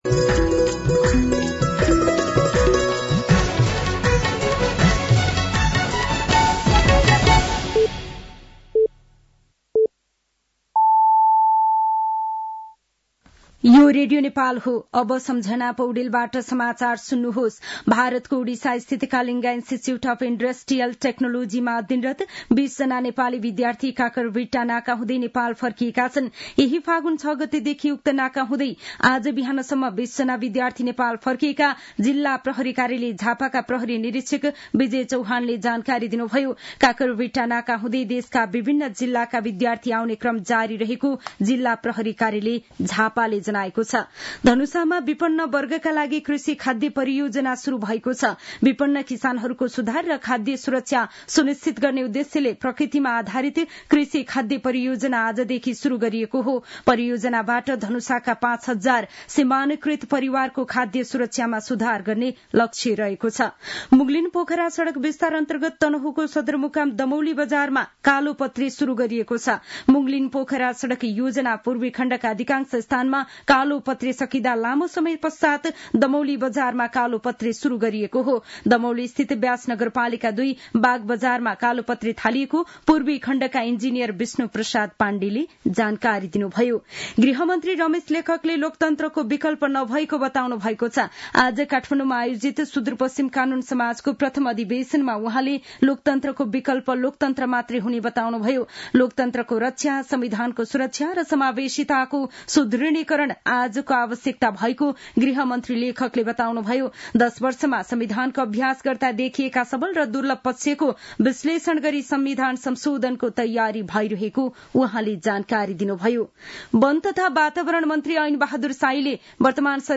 साँझ ५ बजेको नेपाली समाचार : ११ फागुन , २०८१